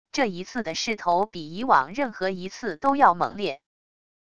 这一次的势头比以往任何一次都要猛烈wav音频生成系统WAV Audio Player